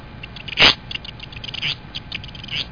zimorodok-alcedo-atthis.mp3